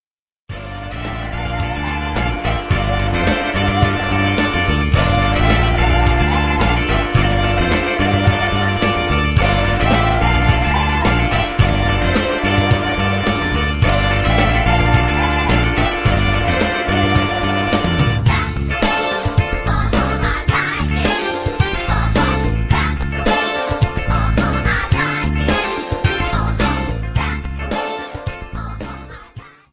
Time to check out the lead part dudes.